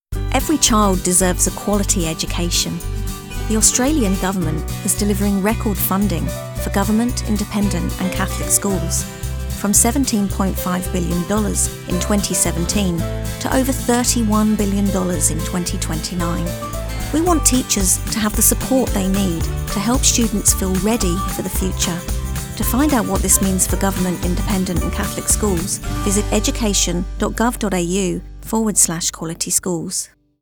Female
Yng Adult (18-29), Adult (30-50)
Her calm, friendly, and articulate tone is easily understood by global audiences, making her ideal for corporate narration, e-learning, and explainer content.
Radio Commercials
Government Radio Ad
Words that describe my voice are Calm, Authoritative, Conversational.